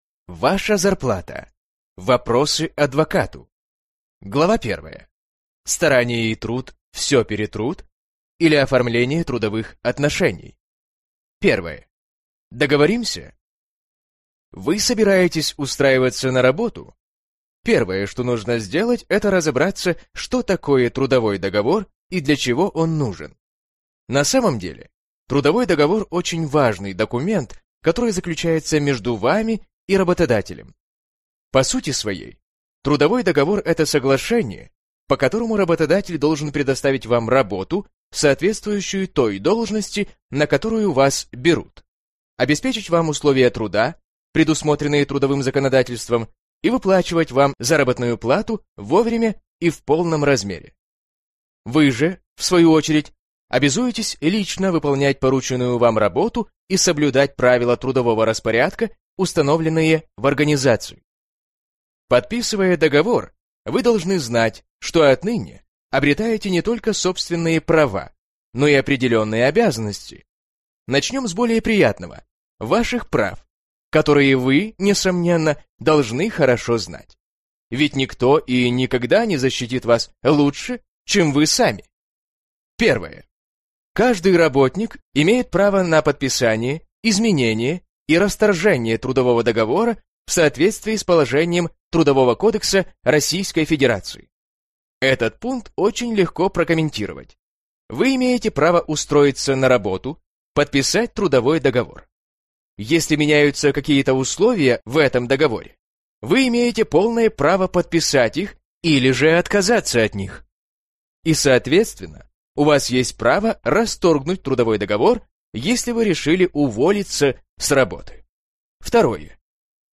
Аудиокнига Ваша зарплата. Советы юриста | Библиотека аудиокниг